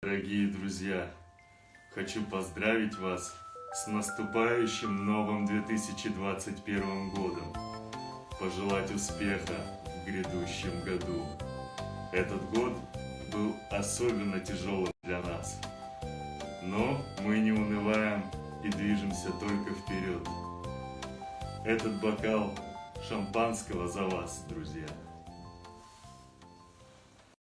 Пример поздравления онлайн(не полный) голос Деда Мороза (256 kbps)
• Категория: Дед Мороз и Санта Клаус
• Качество: Высокое